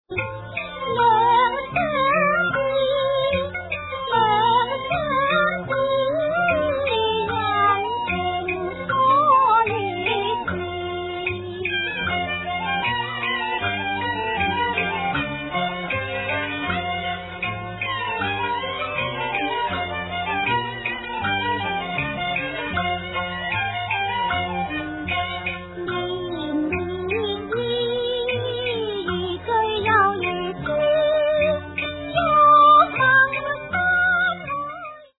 Cantonese singer